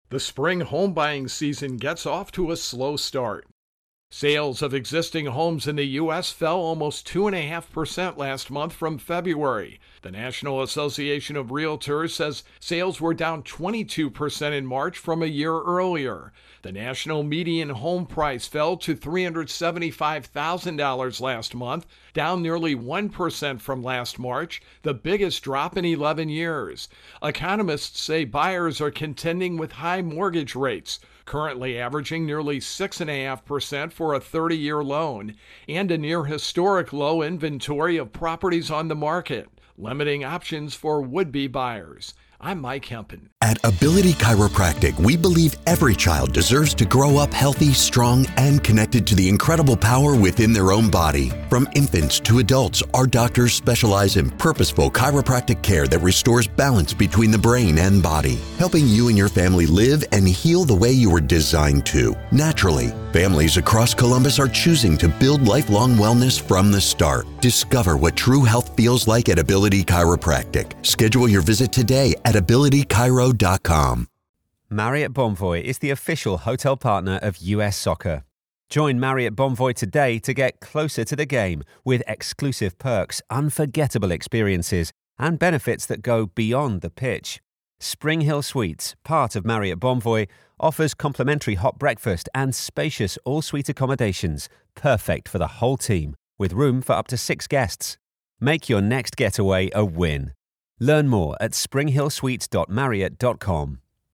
reports on Home Sales